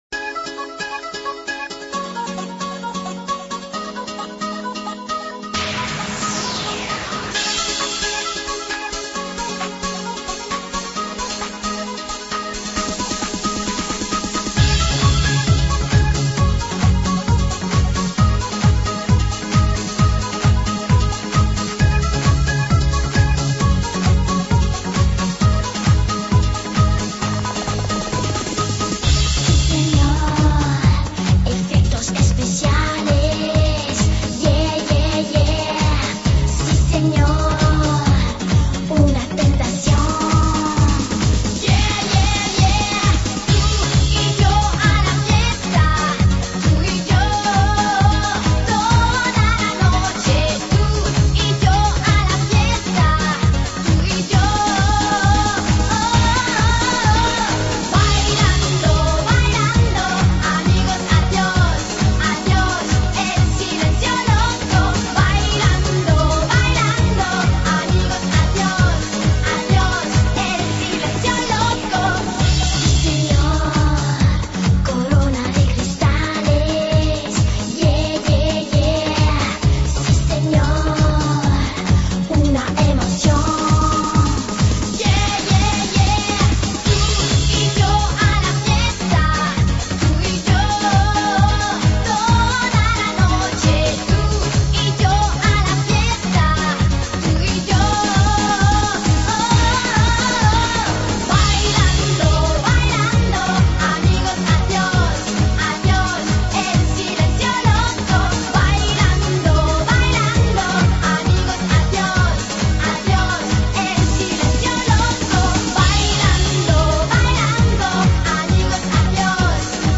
Список файлов рубрики Exclusive EuroDance 90-х